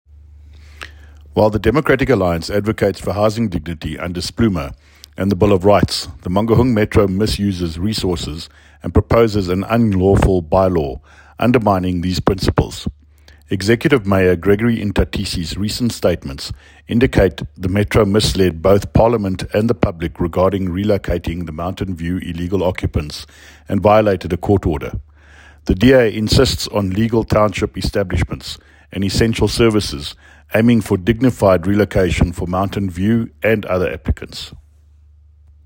Afrikaans soundbites by David Mc Kay MPL, and